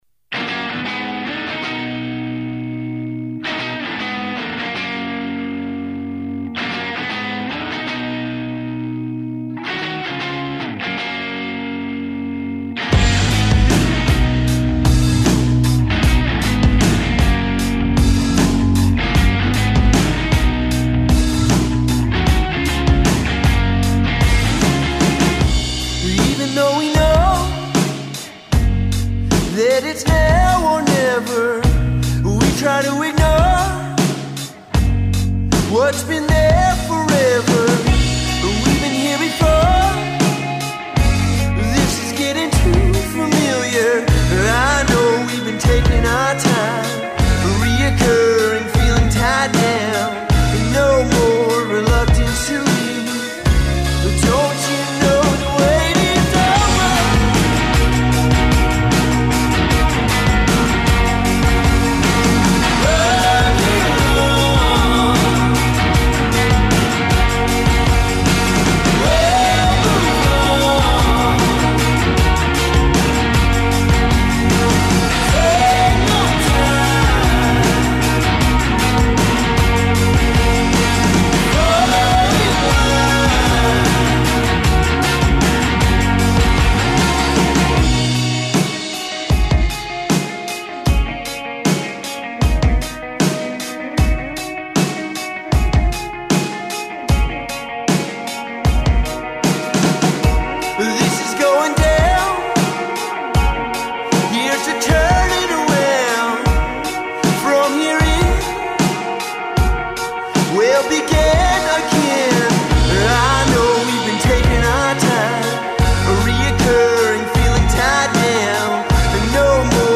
Simple, clean sounding.